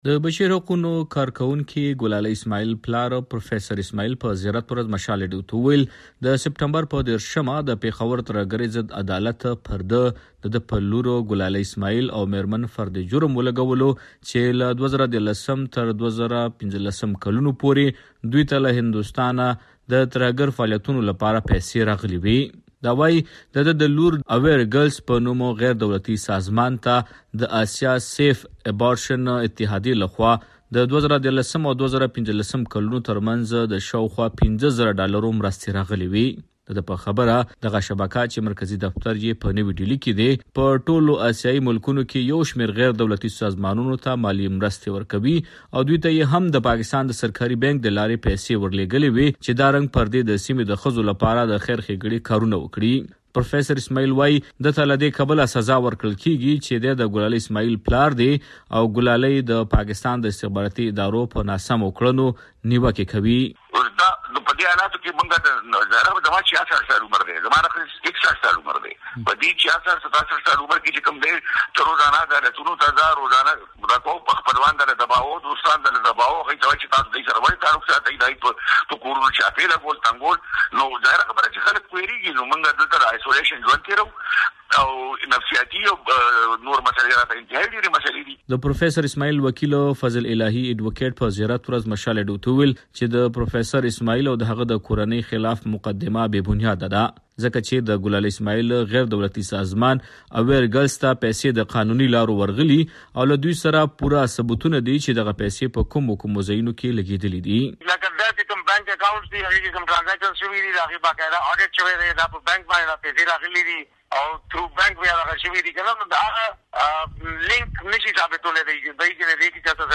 رپورټ